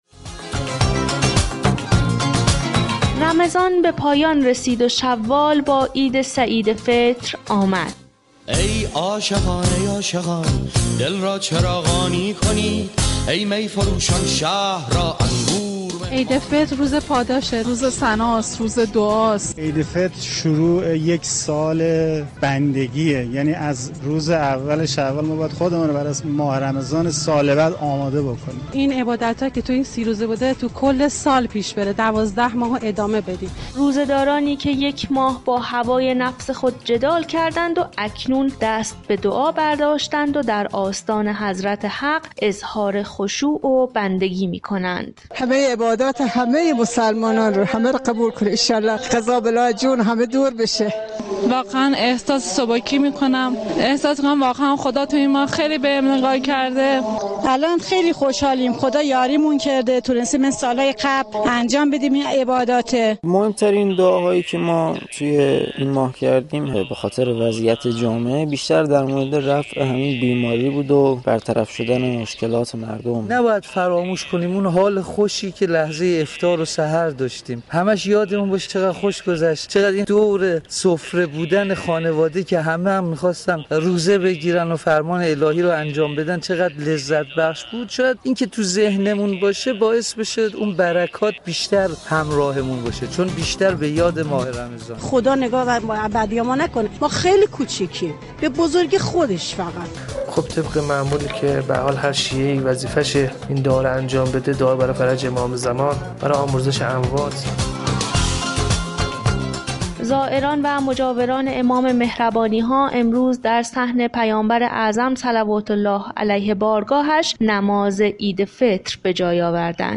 نماز عید سعید فطر در حرم مطهر شمس الشموس، حضرت امام رضا (ع) اقامه شد .
به گزارش خبر رادیو زیارت ، این مراسم معنوی همزمان با طلوع آفتاب ، با تلاوت آیات قرآن کریم و بیان احکام شرعی روز عید فطر آغاز و نماز عید فطر دقایقی قبل از ساعت 7 صبح به امامت آیت الله سید احمد علم الهدی نماینده، ولی فقیه در خراسان رضوی و امام جمعه مشهد مقدس با حضور نمازگزاران و رعایت دستورالعمل‌های بهداشتی اقامه شد.